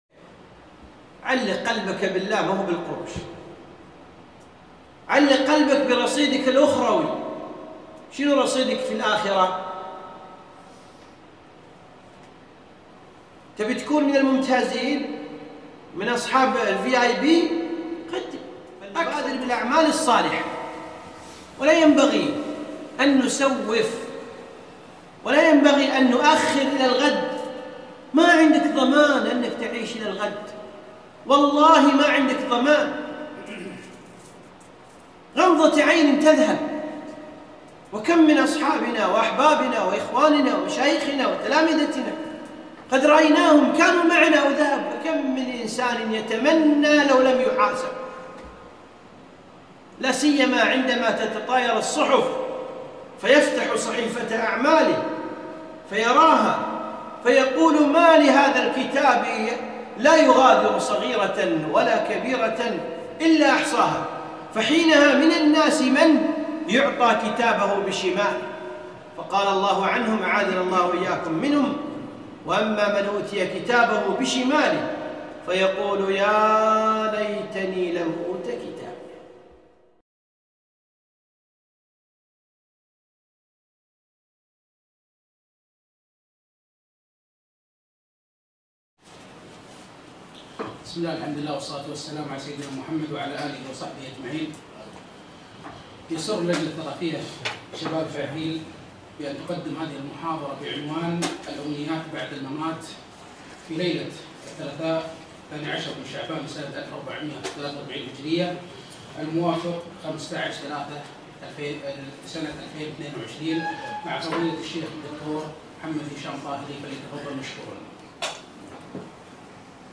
محاضرة قيمة - الأمنيات بعد الممات